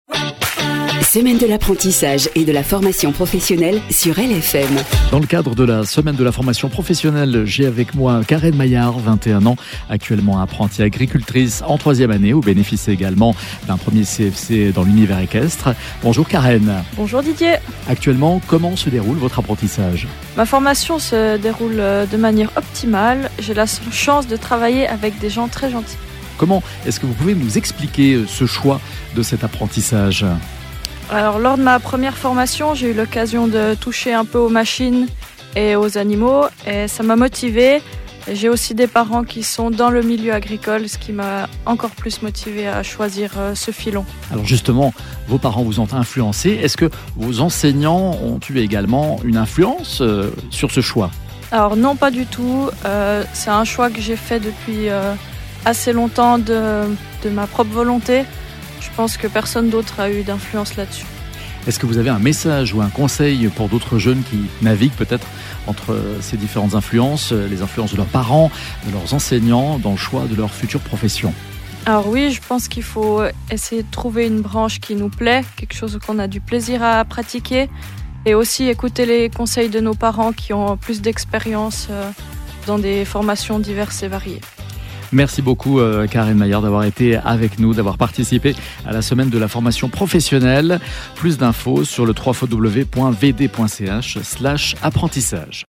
Dans le canton de Vaud, des invités parleront de leur expérience ou de leur vision du choix d’un apprentissage, chaque jour à 16h18 et à 18h48 sur LFM.
Programme des interviews